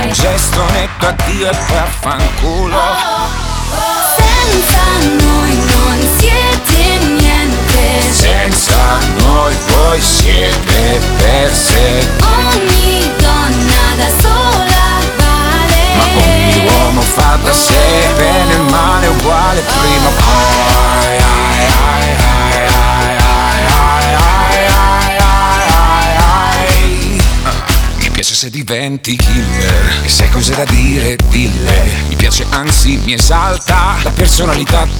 Жанр: Иностранный рэп и хип-хоп / Поп / Рэп и хип-хоп